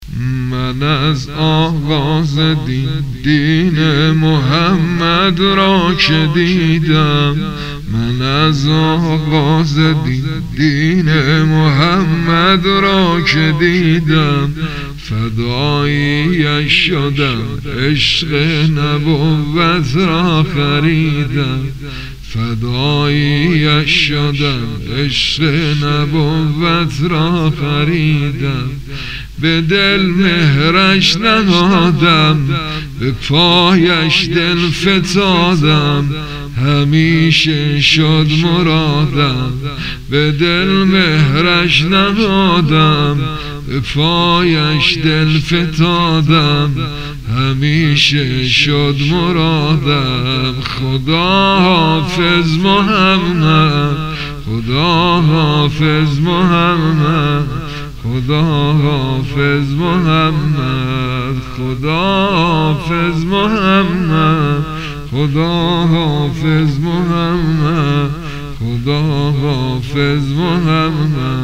سبک ۵ ـ ذکر / ثلاث سنتی ـ دین